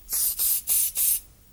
Звуки пульверизатора
Звук пшик-пшик, нажали кнопку, духаются